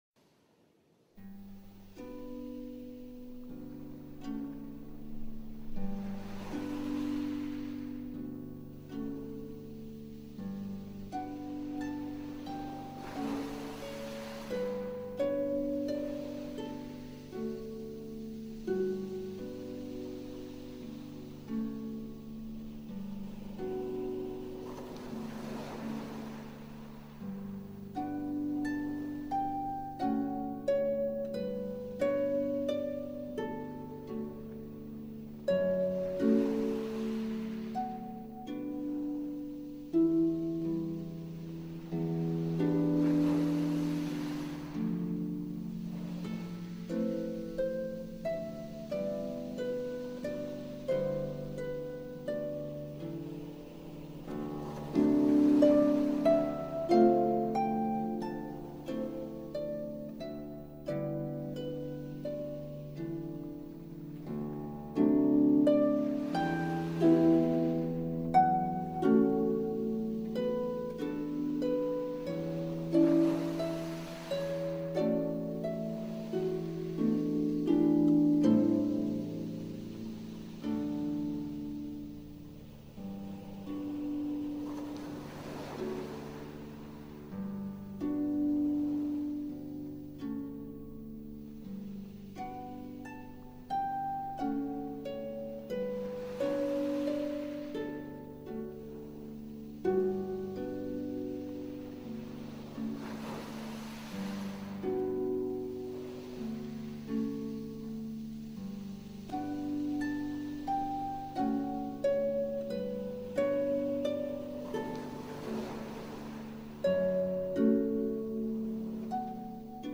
Satie – Arpa y piano para enfoque y lectura tranquila